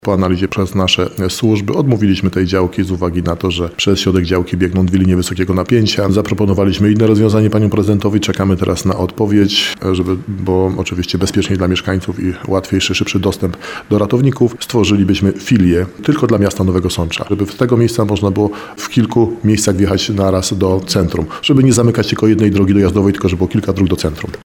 Działka, którą zaproponowały władze miasta, nie nadaje się jednak do zabudowy – mówi radiu RDN Nowy Sącz starosta.